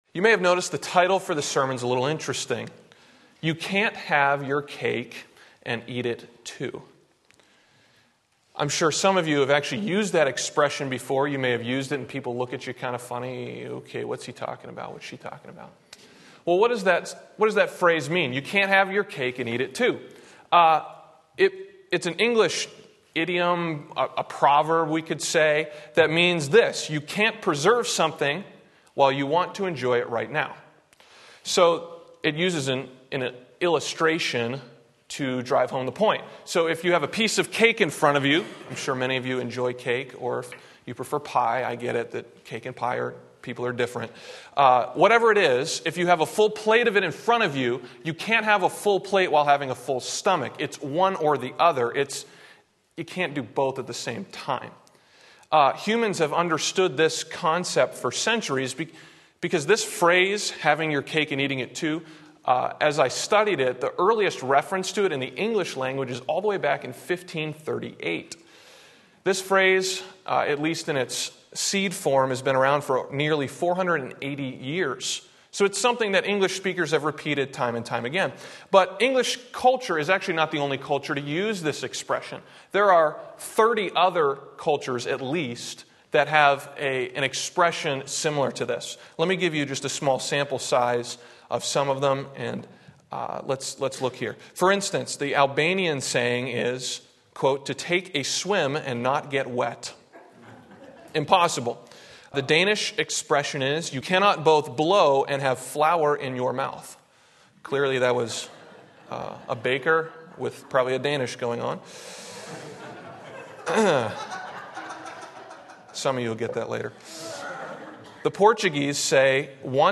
Sermon Link
18-29 Sunday Morning Service